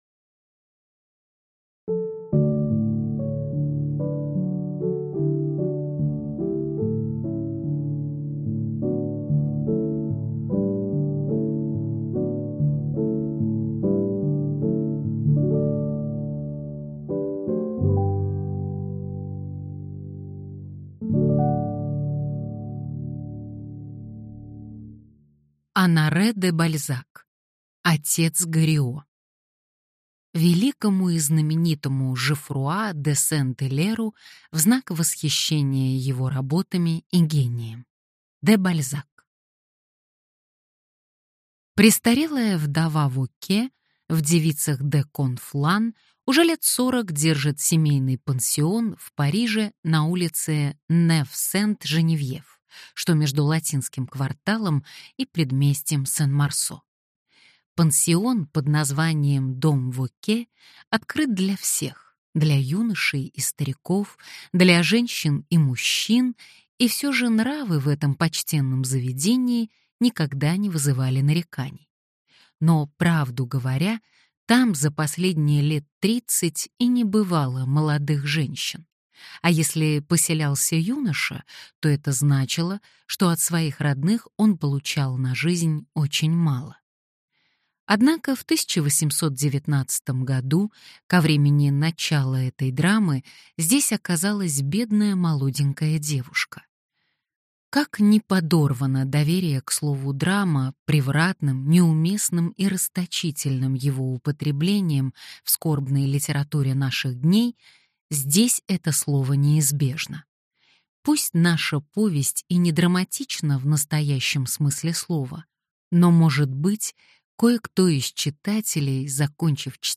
Аудиокнига Отец Горио | Библиотека аудиокниг